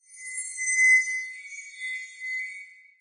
dolphin.ogg